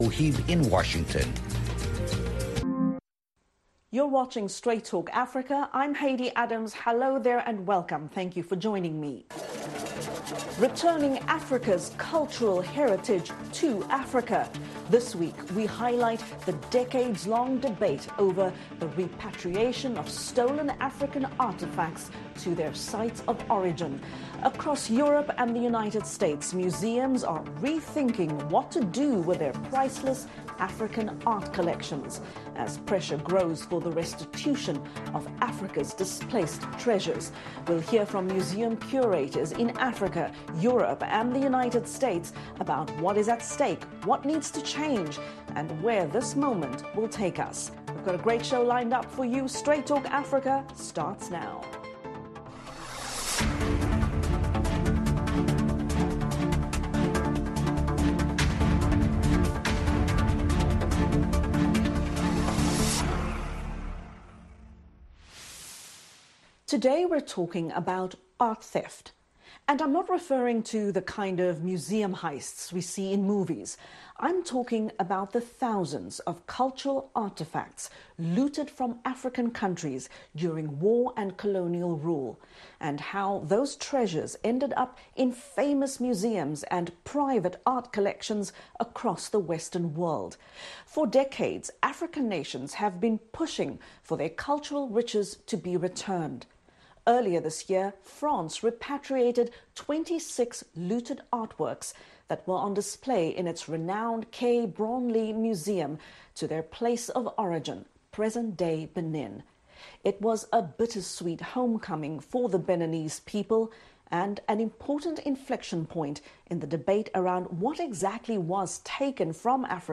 The Return of African Heritage to the Continent [simulcast]